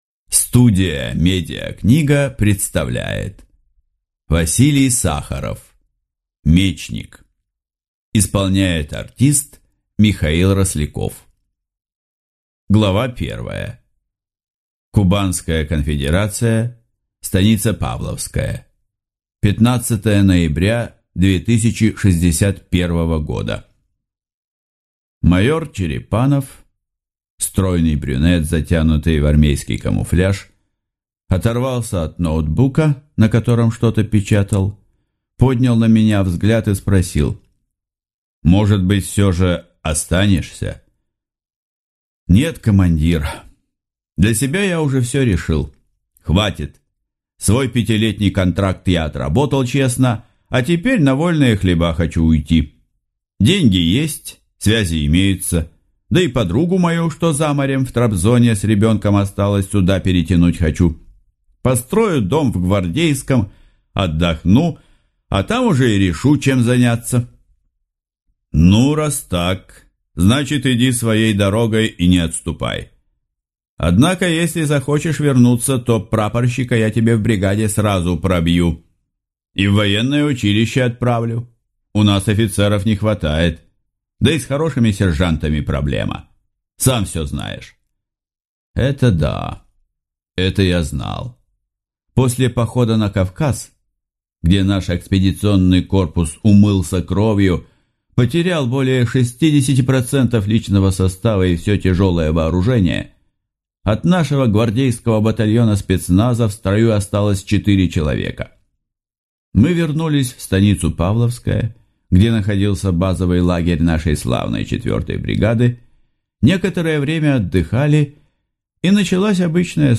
Аудиокнига Мечник | Библиотека аудиокниг